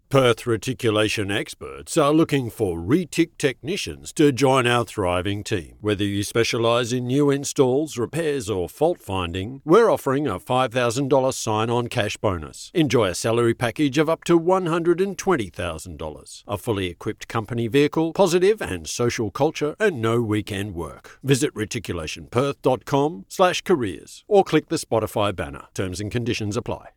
Male
English (Australian)
Adult (30-50), Older Sound (50+)
Silken baritone voice, for Documentary, Training videos and podcasts.
Radio Commercials